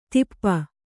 ♪ tippa